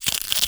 ALIEN_Insect_12_mono.wav